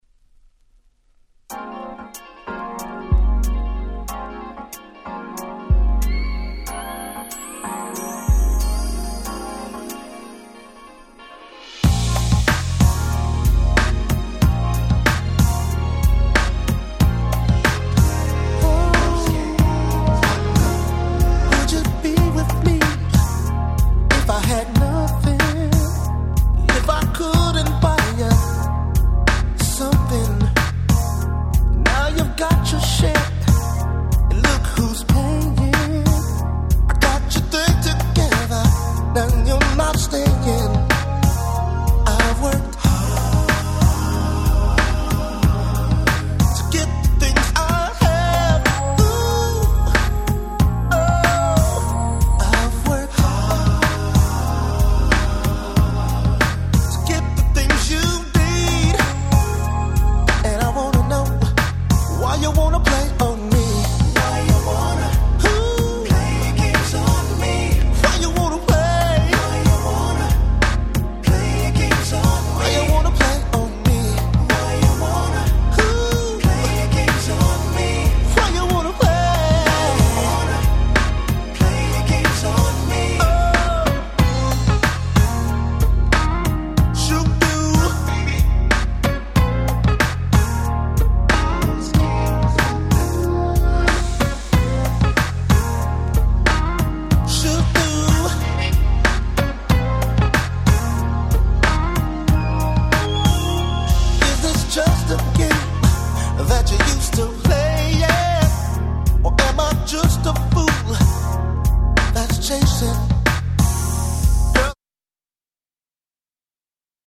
まさにNice 90's R&B !!